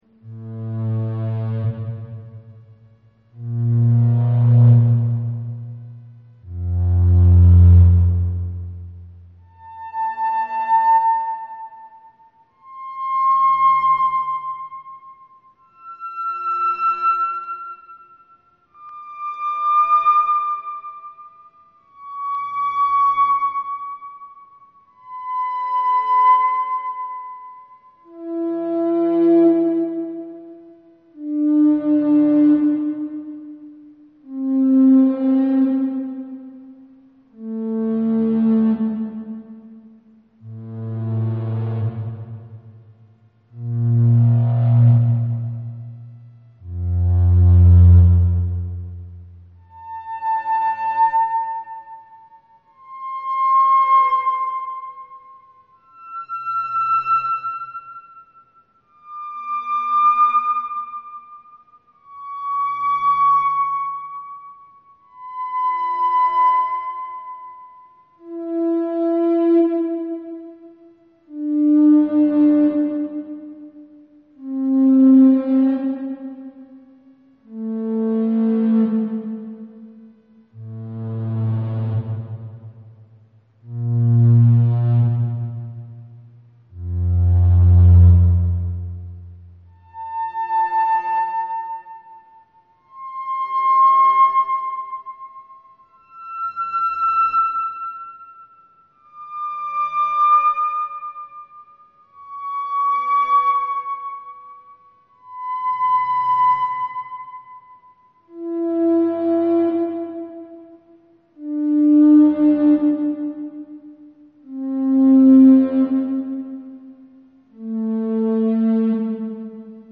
Creator's Tags: drone electronic electronic arpeggio electronic music analog sequences
Description: A piece composed on the Make Noise 0-Coast, designed to accompany the buzzing of the cicadas.